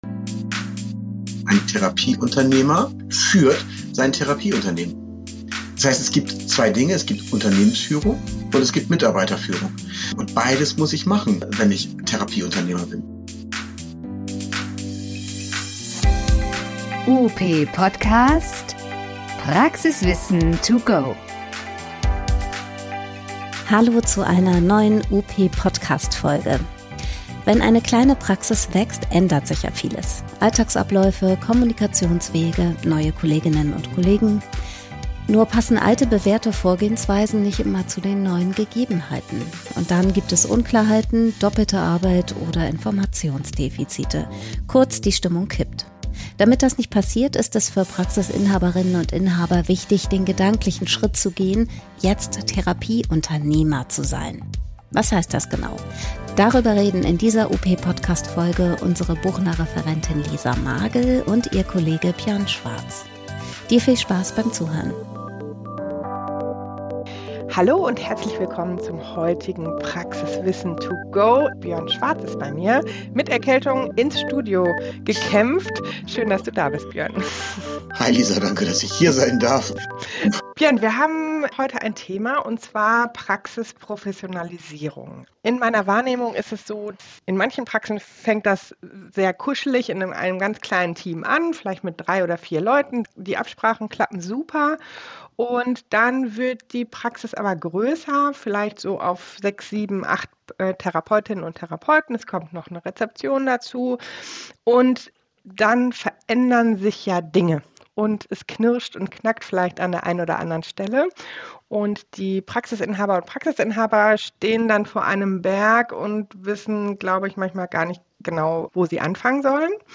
Wir sprechen über den Wandel von der Therapiepraxis zum Therapieunternehmen. Im Gespräch